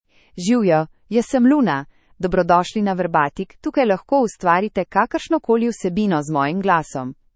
Luna — Female Slovenian AI voice
Luna is a female AI voice for Slovenian (Slovenia).
Voice sample
Listen to Luna's female Slovenian voice.
Luna delivers clear pronunciation with authentic Slovenia Slovenian intonation, making your content sound professionally produced.